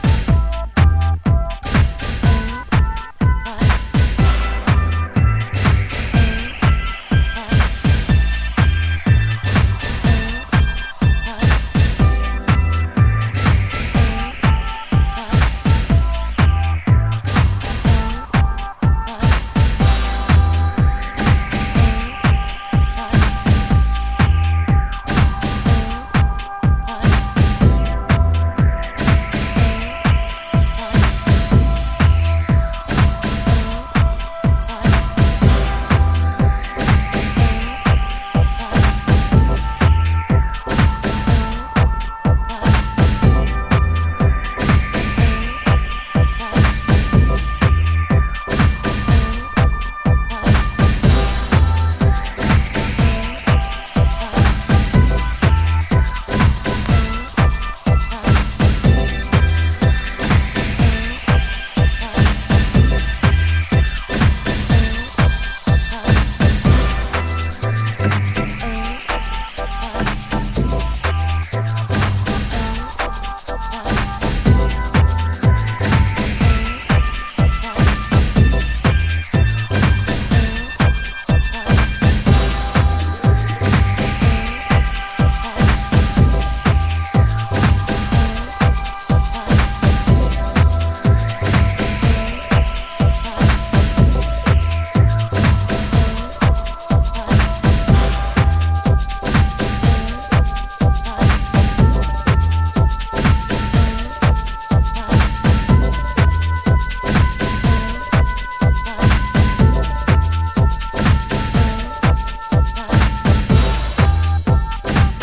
Early House / 90's Techno
勢いのあるユニット名、勢いのあるサウンド。